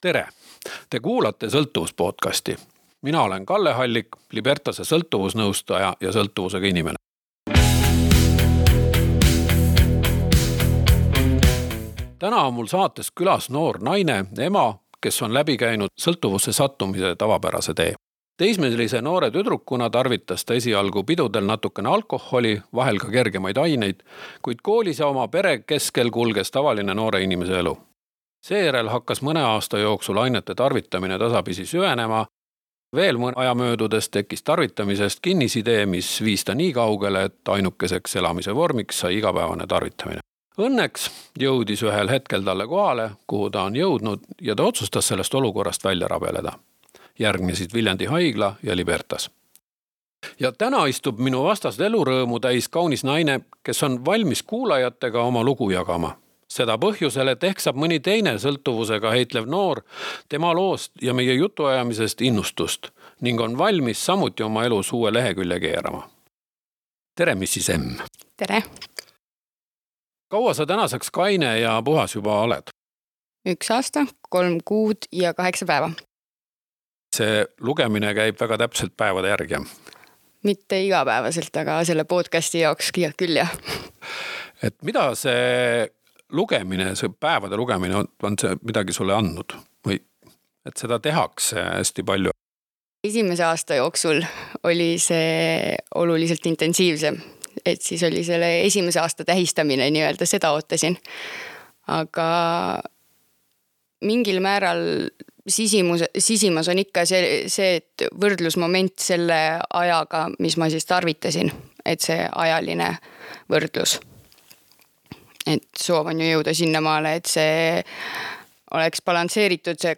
ELVA RAADIO PODCAST - Elva valla elanikele on Libertase sõltuvusest vabanemise programmid tasuta